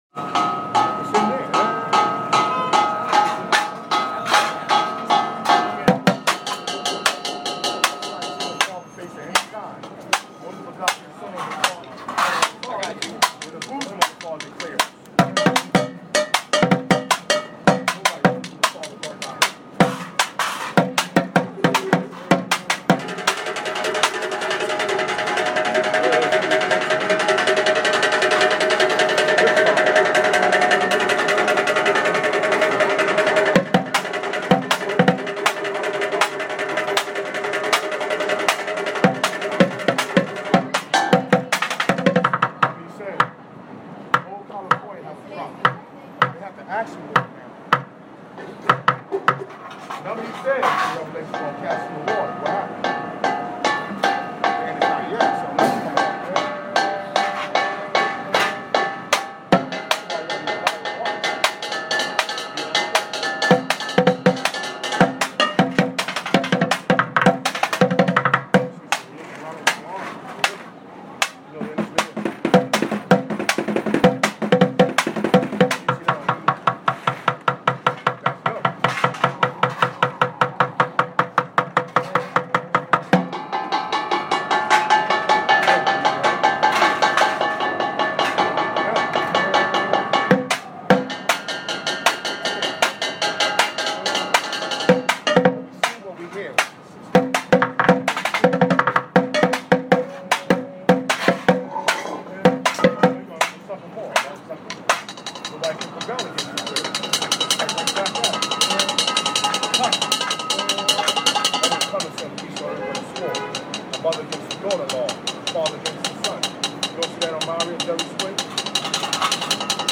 Busker on47 th St NYC on trash drum kit